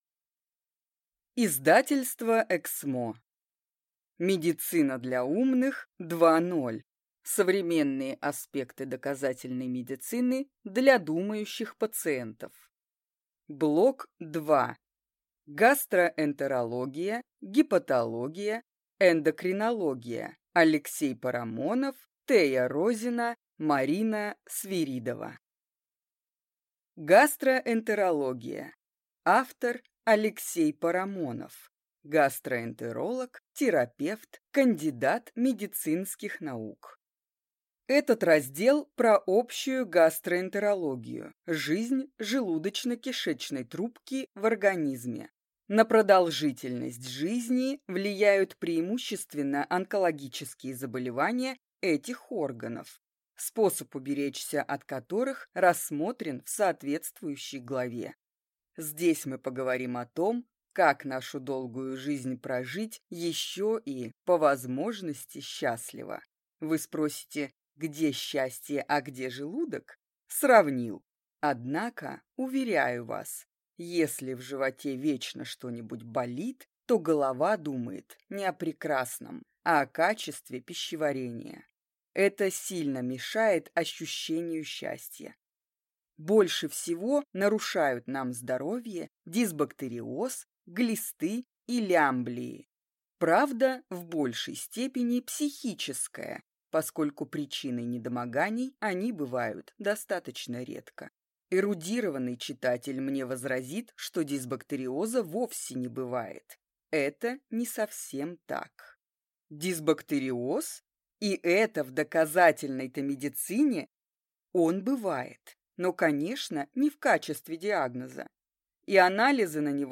Аудиокнига Медицина для умных 2.0. Блок 2: Гастроэнтерология. Гепатология. Эндокринология | Библиотека аудиокниг